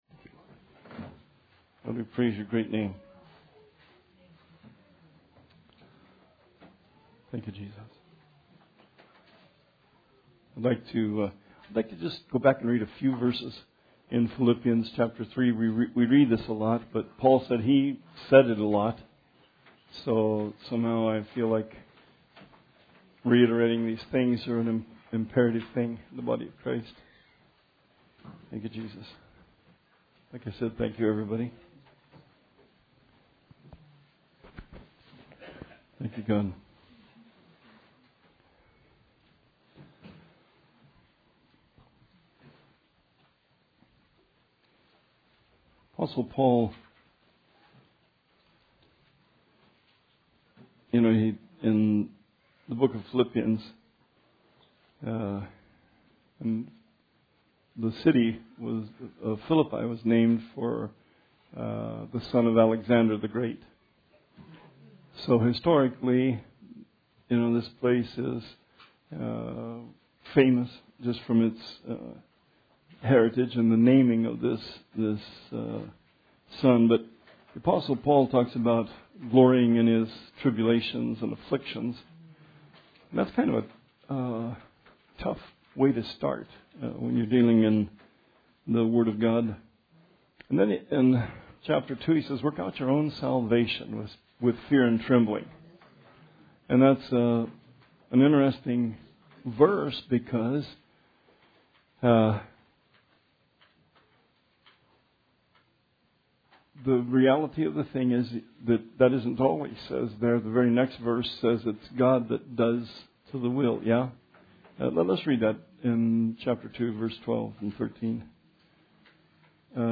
Bible Study 12/11/19